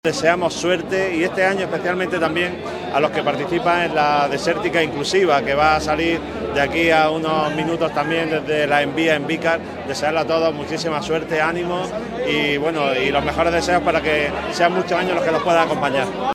JOSE-MARIA-MARTIN-SUBDELEGADO-GOBIERNO-SALIDA-DESERTICA.mp3